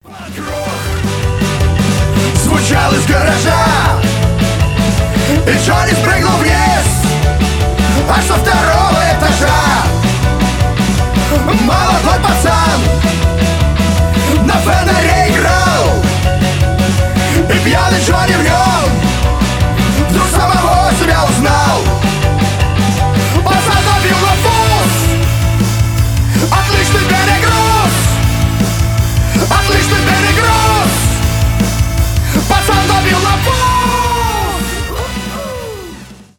рок
панк-рок